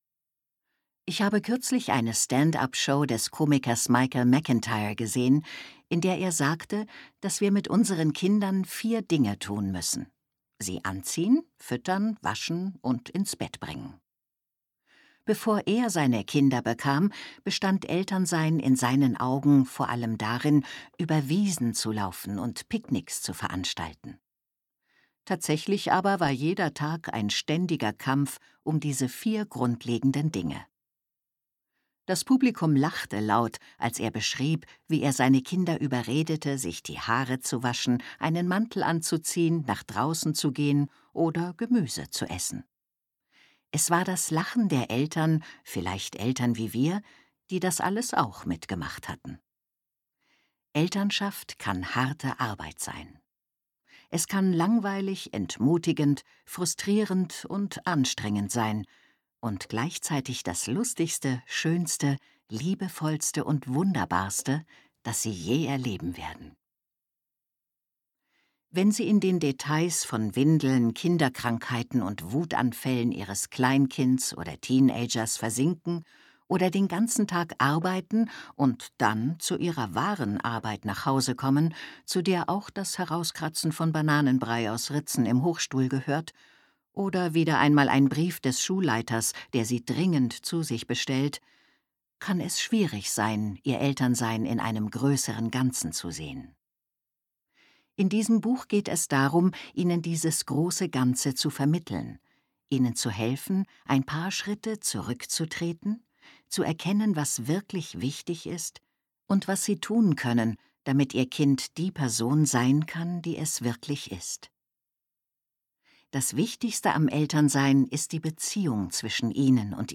2020 | 3. Auflage, Ungekürzte Ausgabe
lt;p>Der Nummer-1-Bestseller der Sunday Times: ein Hörbuch über Erziehung für alle, die keine Erziehungsratgeber mögen